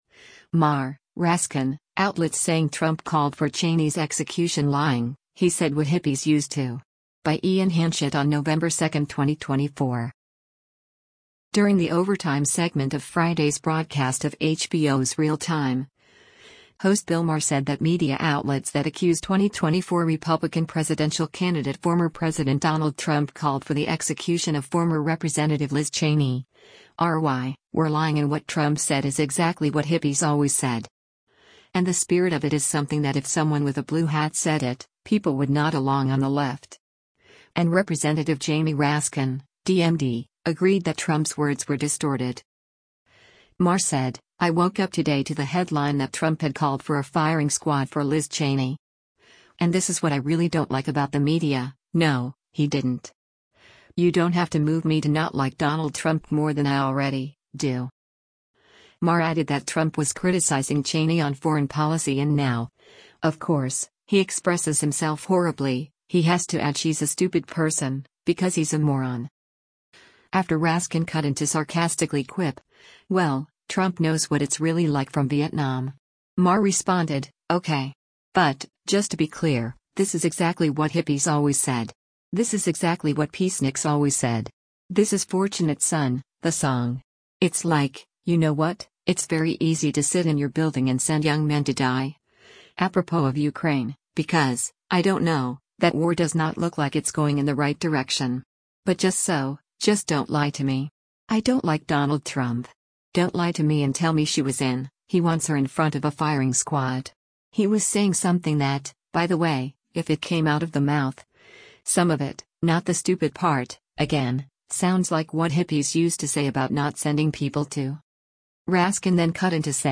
During the “Overtime” segment of Friday’s broadcast of HBO’s “Real Time,” host Bill Maher said that media outlets that accused 2024 Republican presidential candidate former President Donald Trump called for the execution of former Rep. Liz Cheney (R-WY) were lying and what Trump said “is exactly what hippies always said.”